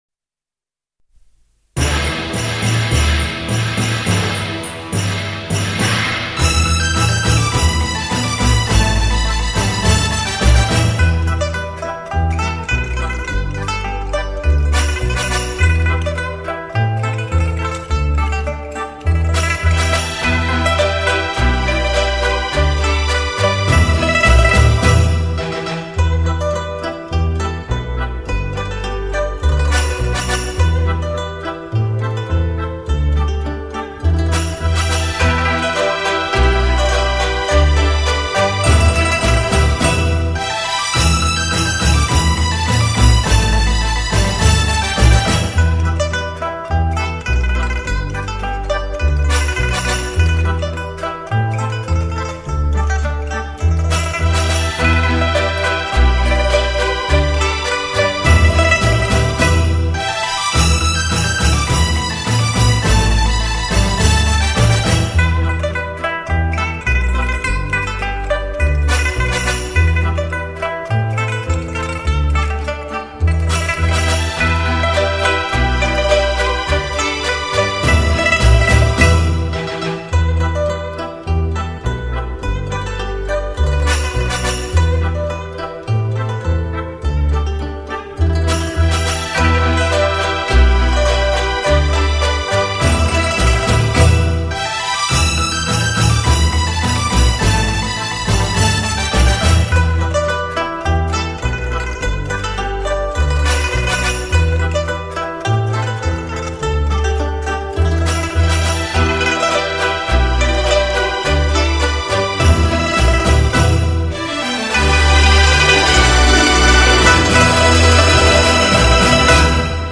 当你欣赏这组西藏轻音乐的时候，仿佛一路走于美丽的青藏高原和雅鲁藏布江；围坐在跳动的篝火旁，
琵琶与古筝
为低音质MP3